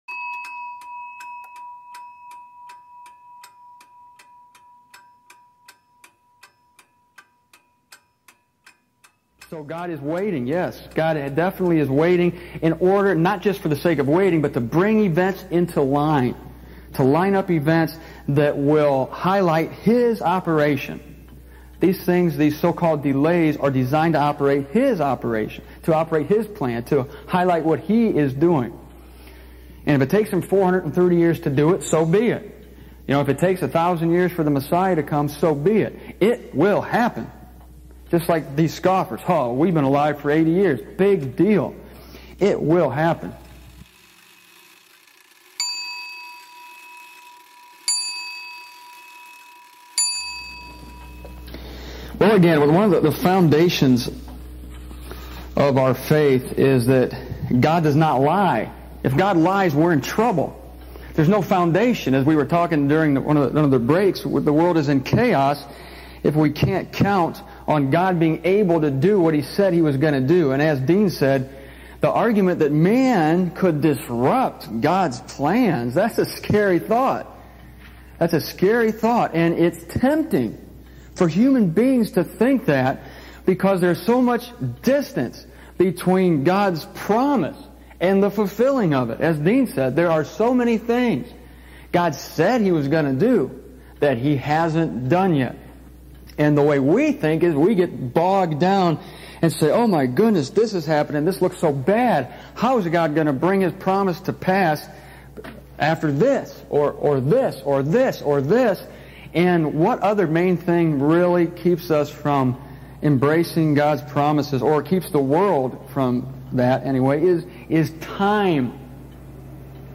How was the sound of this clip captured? Twenty-one years since this recording was made in Almont, Michigan has not dampened the certainly of what is said here.